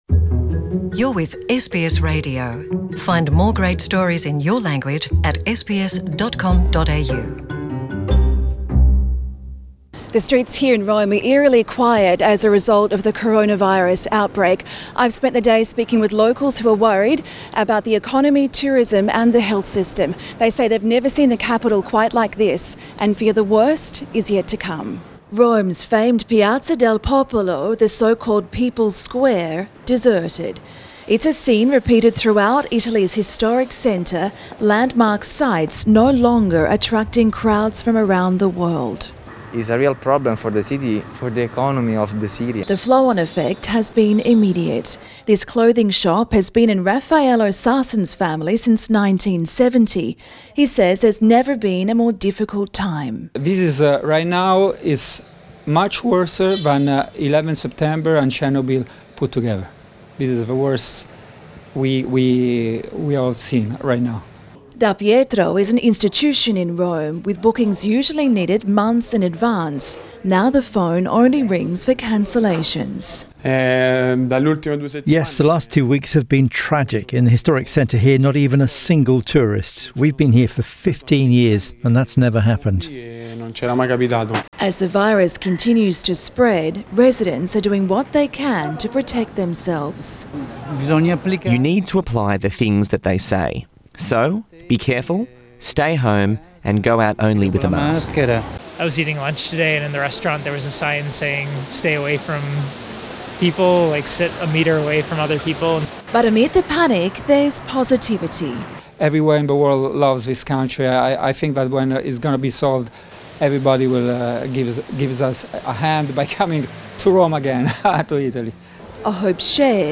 SBS has been on the streets of Rome, talking to the small number of people who have ventured out.